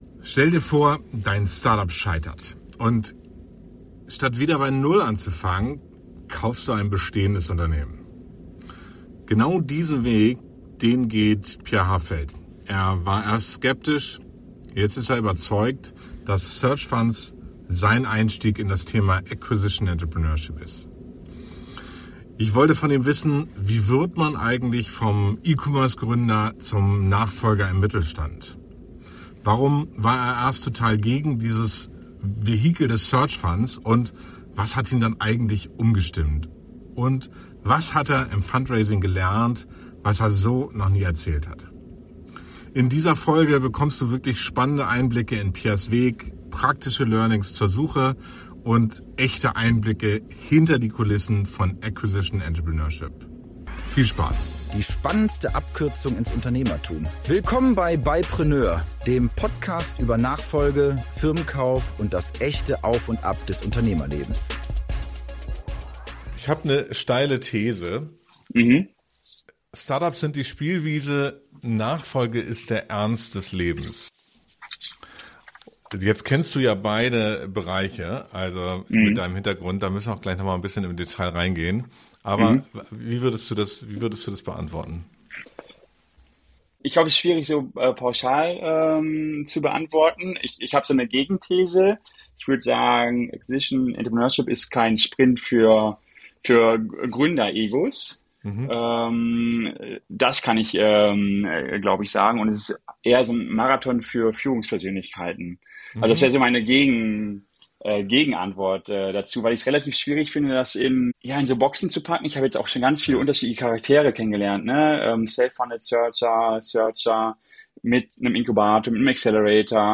Ein Gespräch voller ehrlicher Einblicke in die Realität von Search Funds, die Herausforderungen der Nachfolge und die Frage: Welches Modell passt wirklich zu mir?